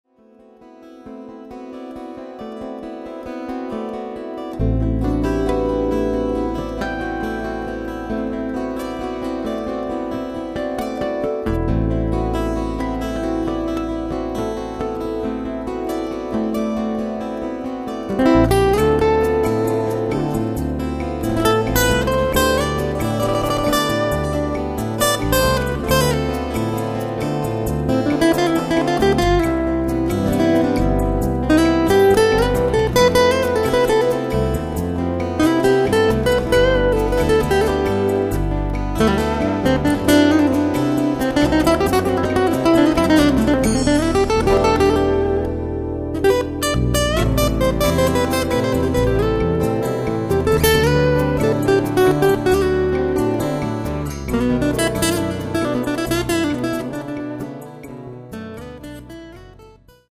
in einer unplugged-Version